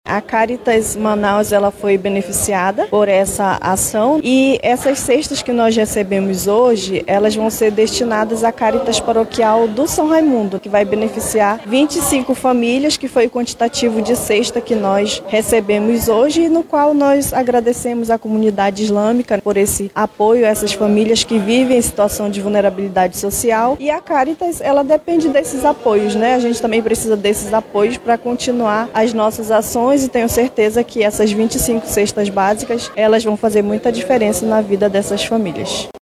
SONORA-2-DOACAO-CESTAS-MULCUMANOS-.mp3